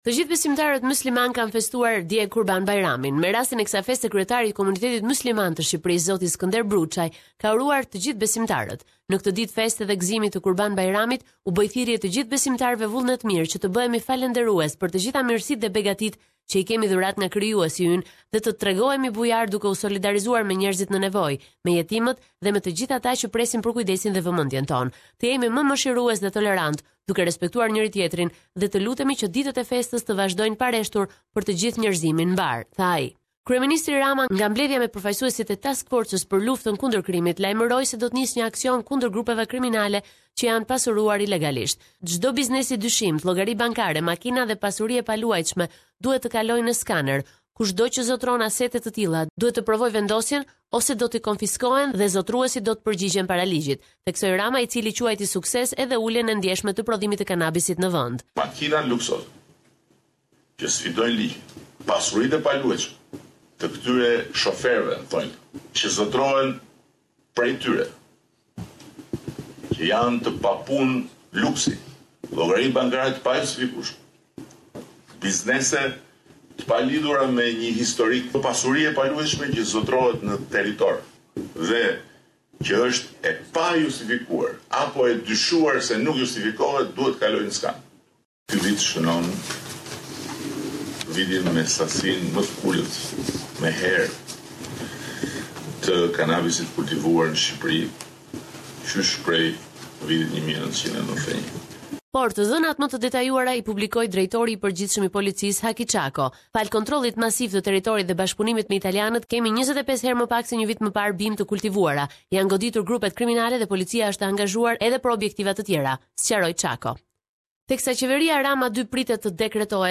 Weekly news summary from Albania